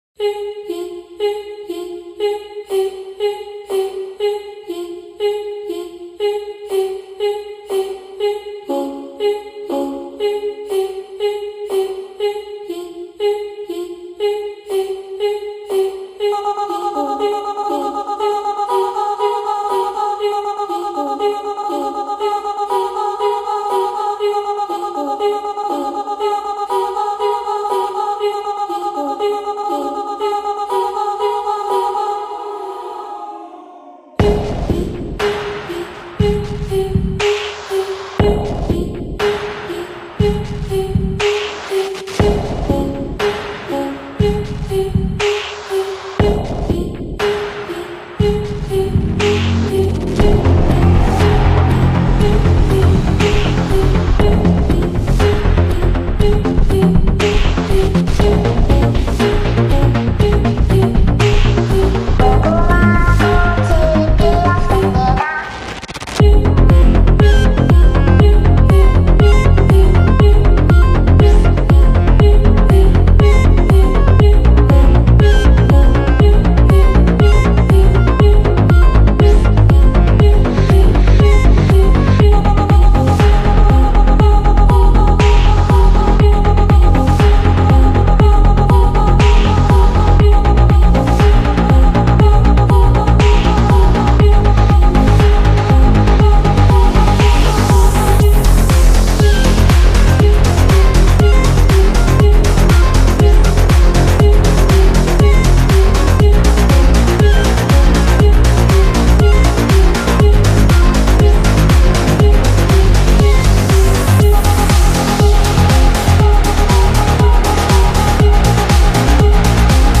Soundtrack, Minimalist